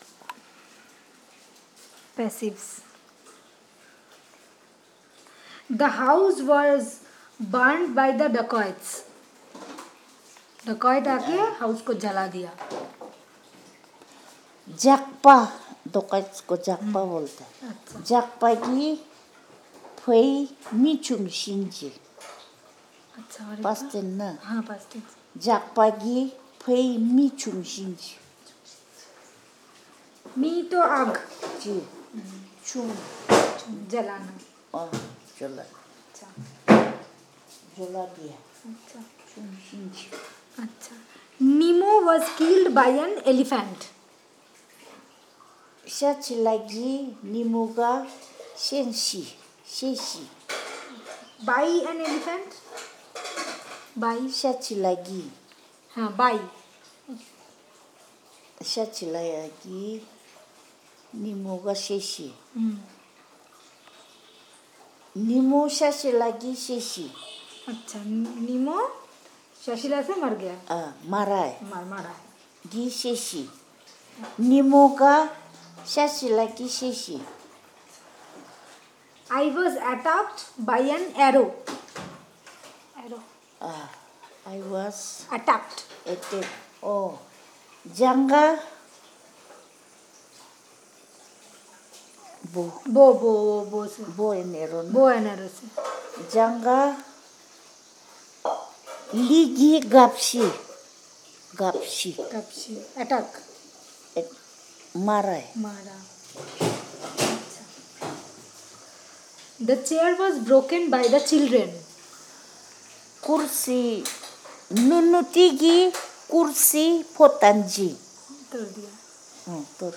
dc.coverage.spatialDirang Village, West Kameng District
dc.descriptionThis is an elicitation of sentences about passives using the questionnaire.
dc.description.elicitationmethodLonger-text elicitation method
dc.type.discoursetypeTranslation pair